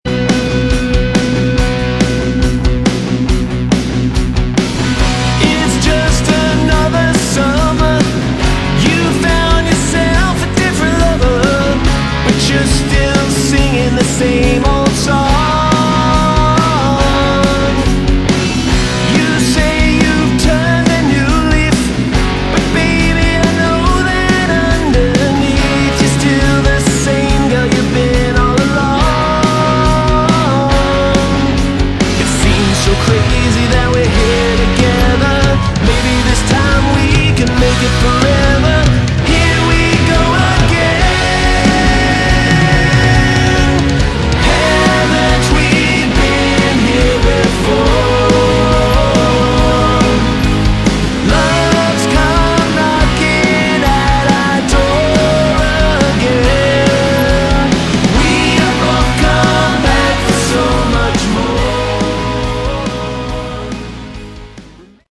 Category: Melodic Rock
vocals
bass
guitars
keyboards
drums